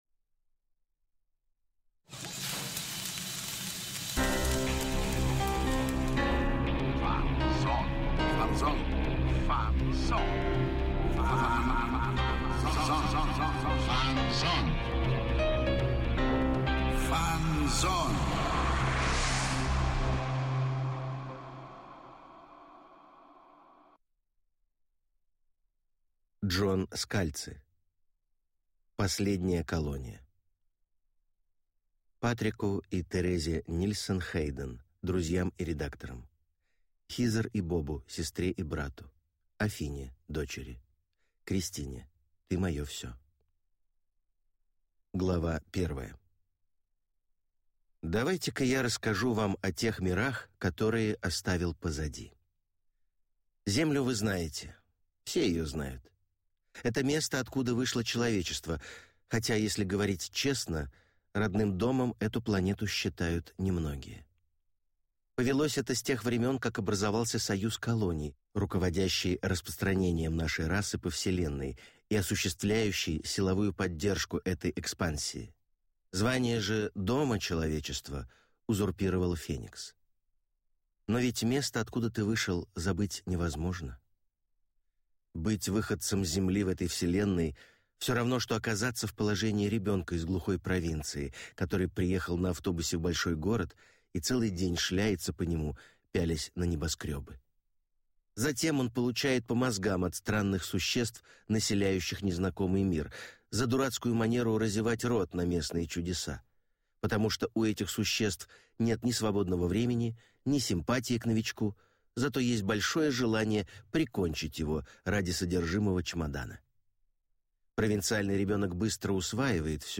Аудиокнига Последняя колония | Библиотека аудиокниг